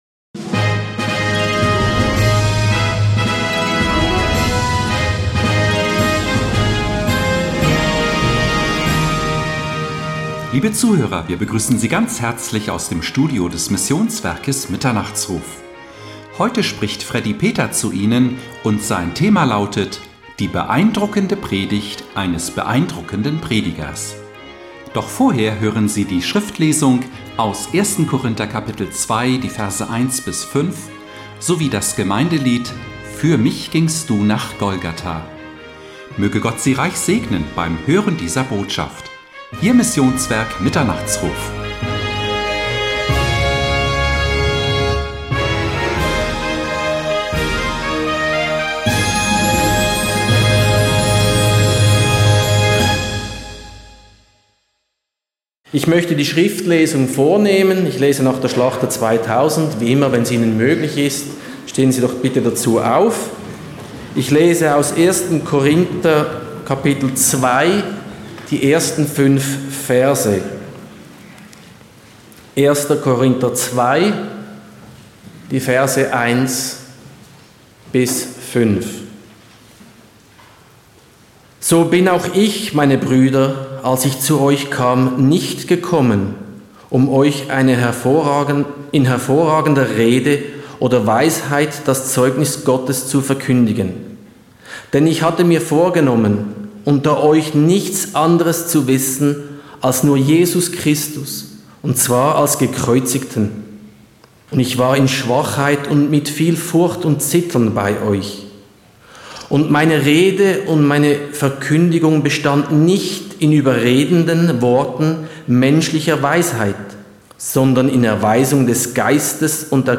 Die beeindruckende Predigt eines beeindruckenden Predigers